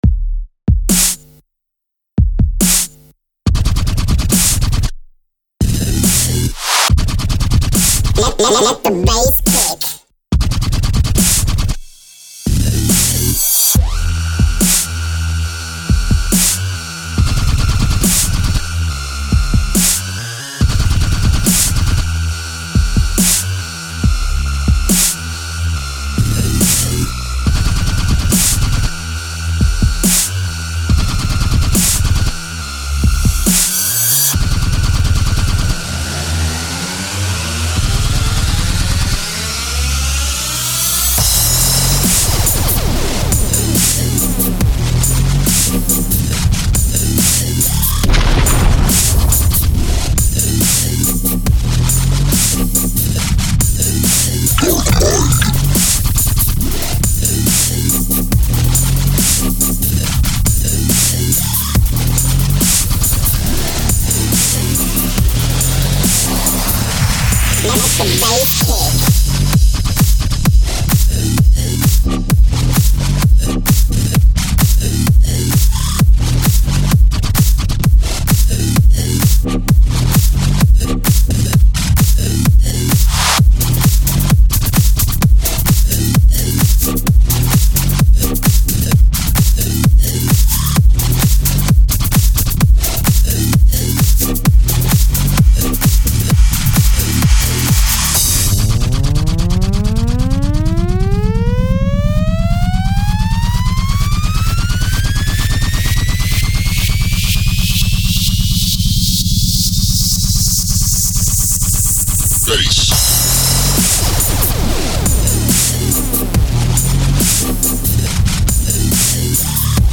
Стиль: Dub & Dubstep